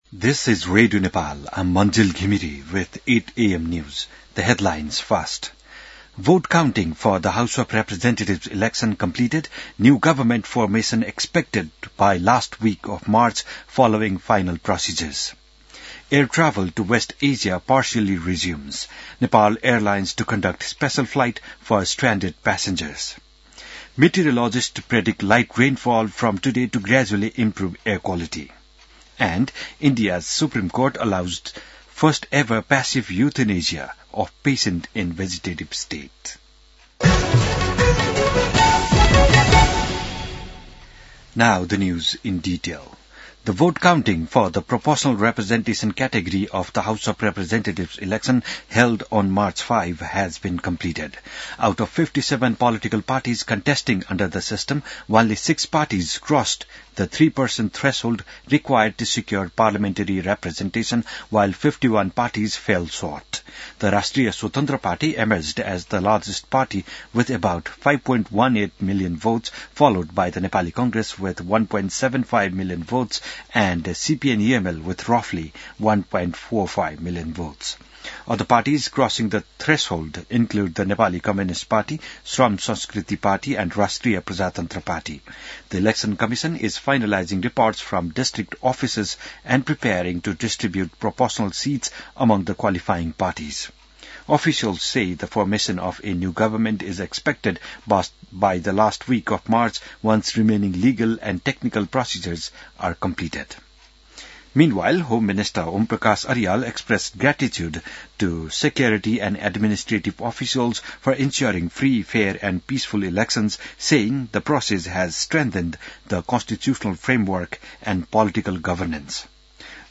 बिहान ८ बजेको अङ्ग्रेजी समाचार : २८ फागुन , २०८२